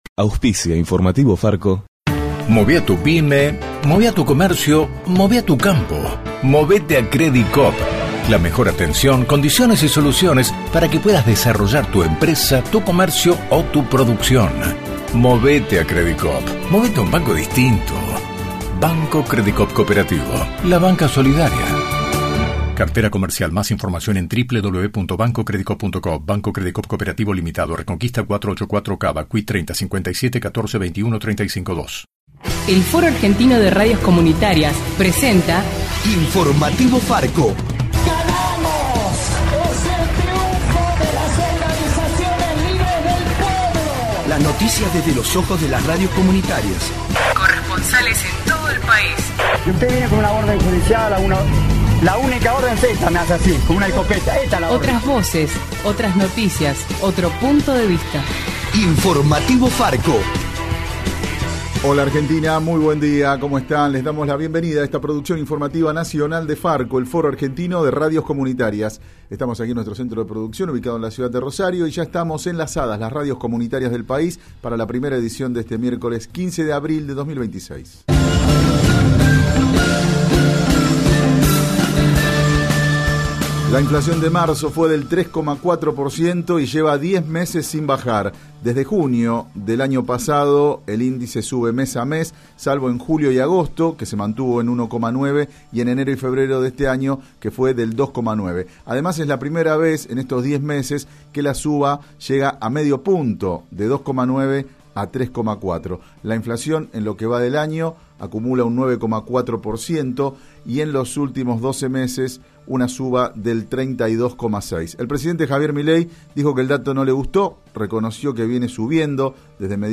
De la mano de FARCO (Foro Argentino de Radios Comunitarias) en Radio Atilra te acercamos el informativo más federal del país.